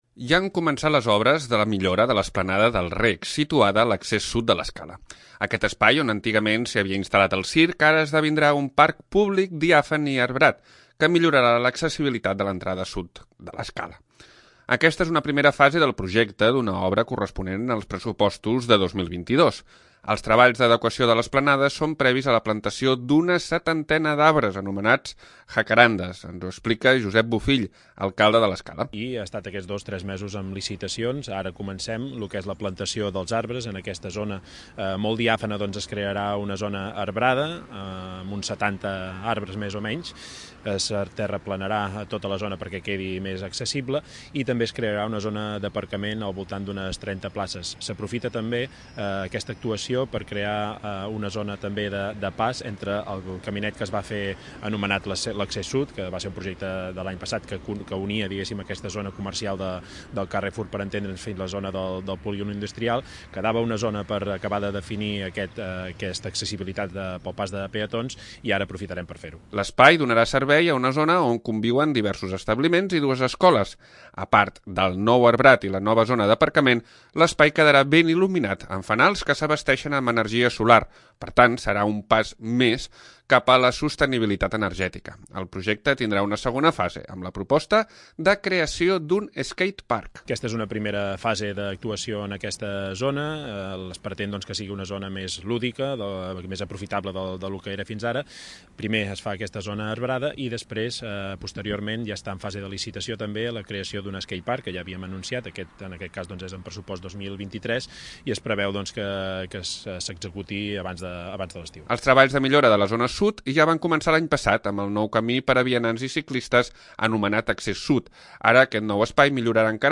2. L'Informatiu
Ens ho explica, Josep Bofill, alcalde de l'Escala.